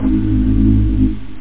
organ-samples
Amiga 8-bit Sampled Voice
reel2reel.mp3